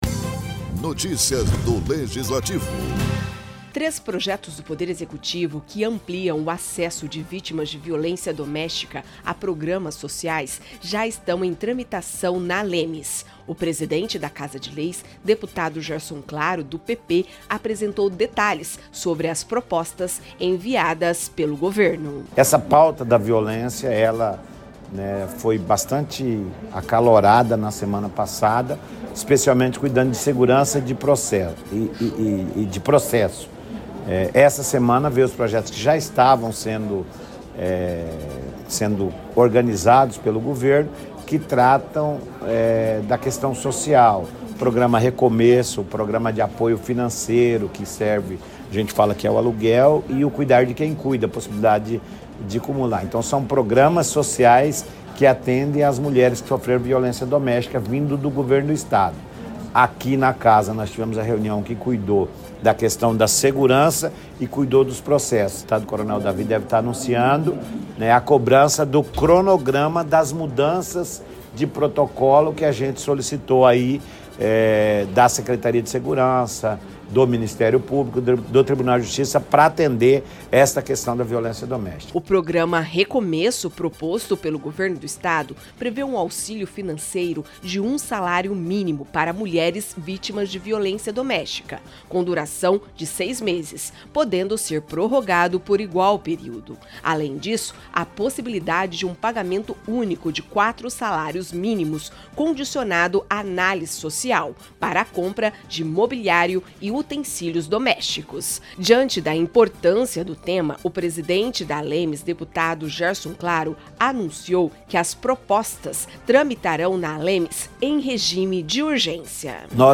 Presidente da ALEMS fala de medidas que reforçam combate à violência contra mulher
Três projetos do Poder Executivo estão em tramitação na Assembleia Legislativa de Mato Grosso do Sul (ALEMS) para fortalecer a assistência a mulheres vítimas de violência doméstica. O presidente da Casa, deputado Gerson Claro (PP), detalhou as propostas.